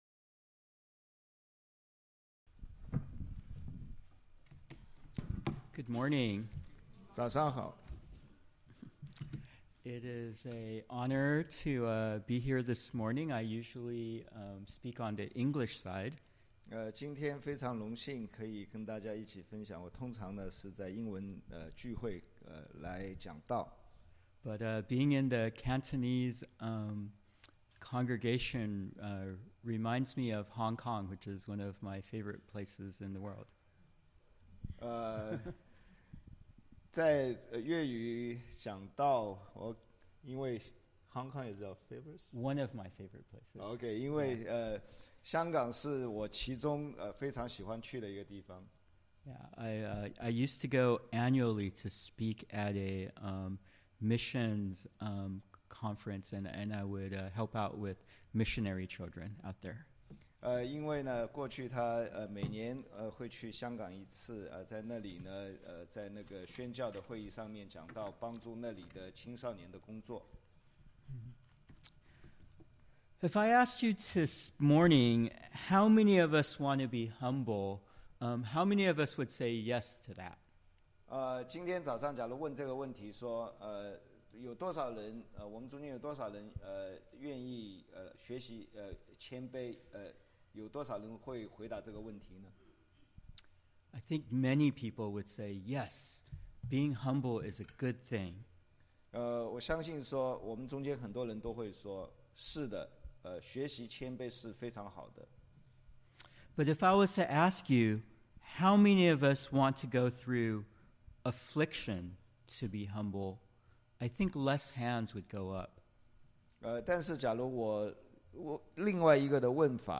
Passage: Psalm 119:65-72 Service Type: Sunday Worship